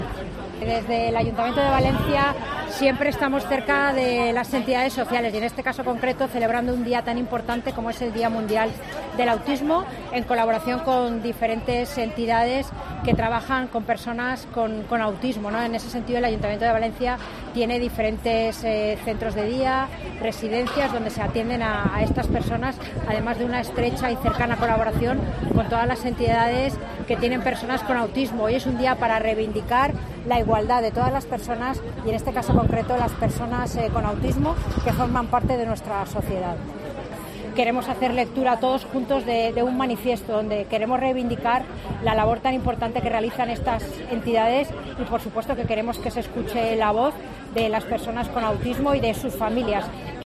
Concejales de todos los grupos políticos municipales han dado lectura de manera conjunta a un texto en reivindicación del compromiso con la diversidad y la inclusión real